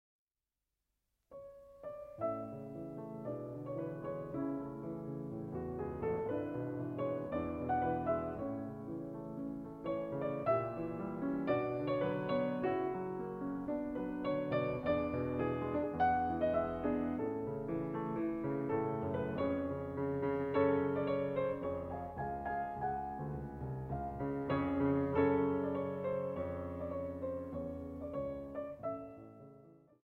Allegro moderato 6:59